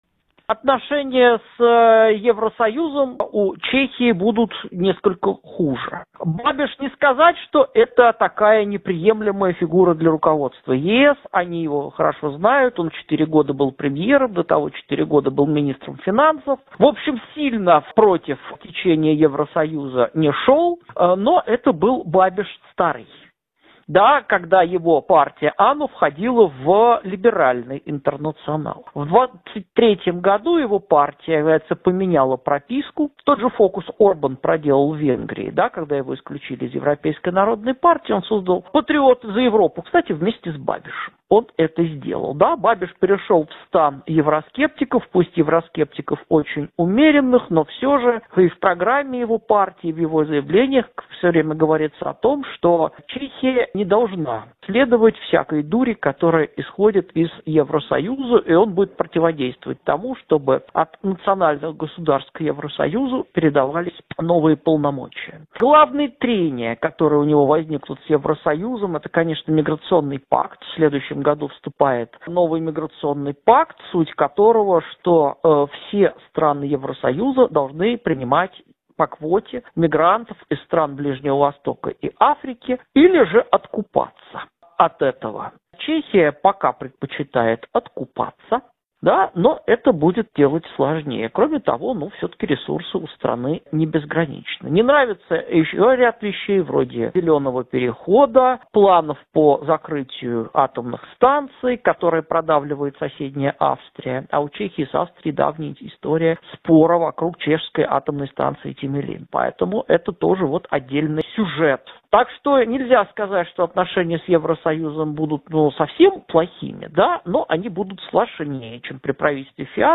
в интервью журналу «Международная жизнь» рассказал об итогах парламентских выборов в Чехии: